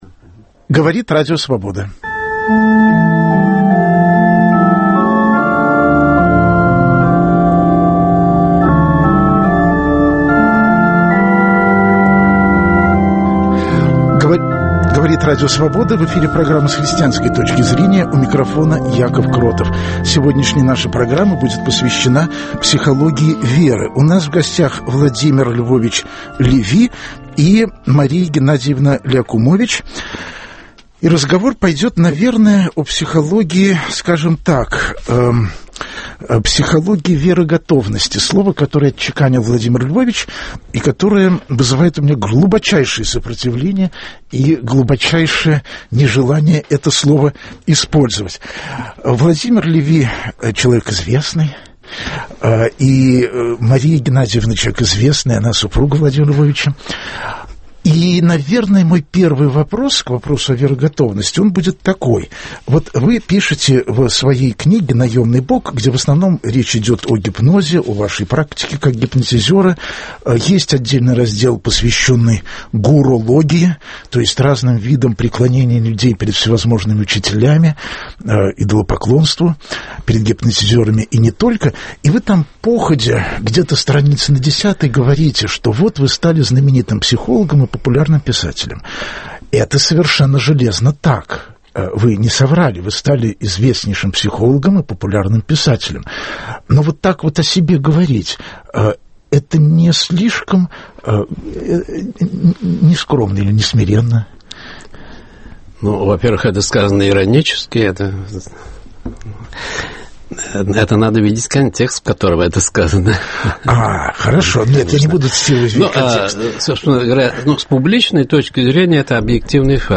Гостем программы будет Владимир Львович Леви.
В чём причина конфликта и в чём смысл встречи психологии и веры, - об этом пойдет разговор в прямом эфире.